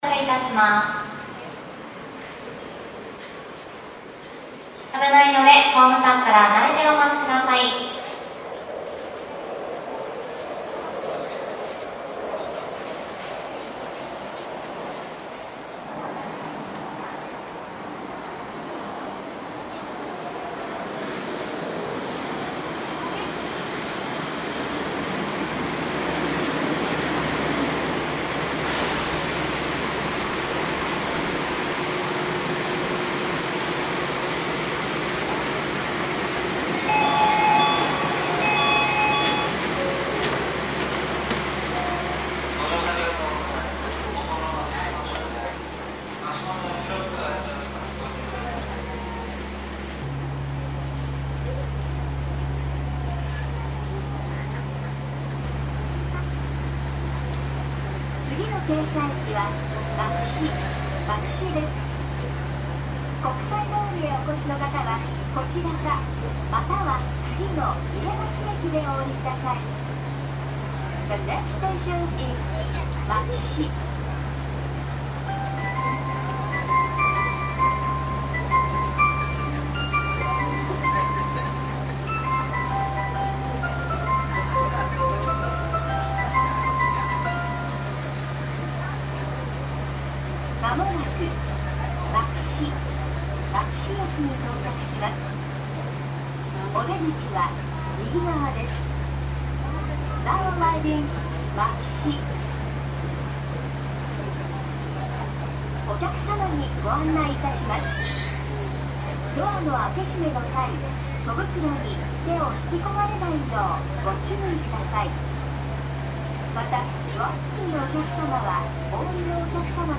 女性打鼾
描述：深睡眠打鼾的女性。堵塞，拥挤的呼吸。
Tag: 呼吸 OWI 打鼾 SLEEP